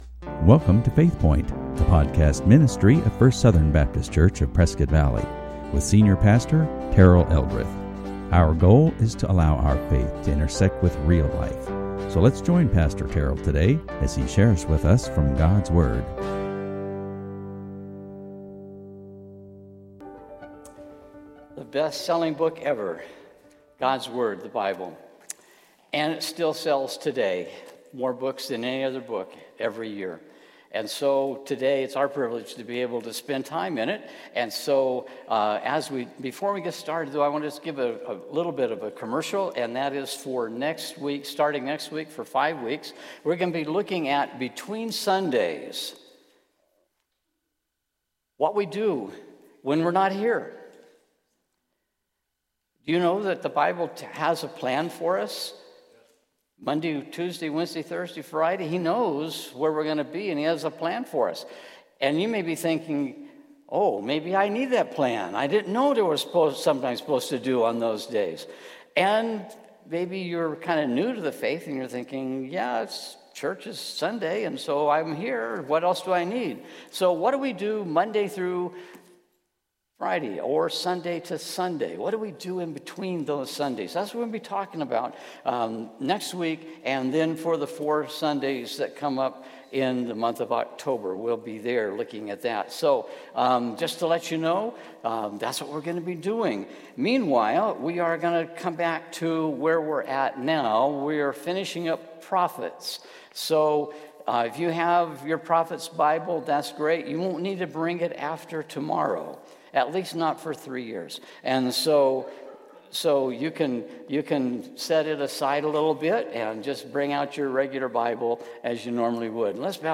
Listen to sermons from FSBC of Prescott Valley